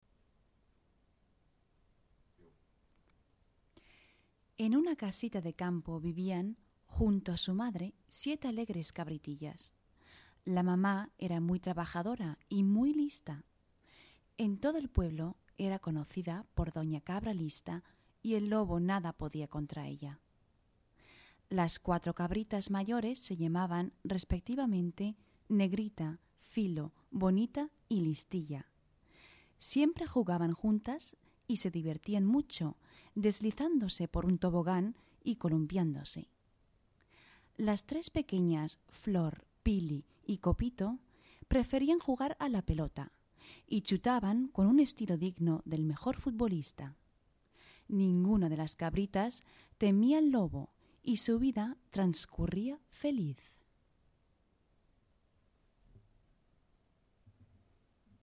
» Spanisch f.
span[1]._M_rchen.mp3